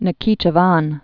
(nə-kēchə-vän, -ē-)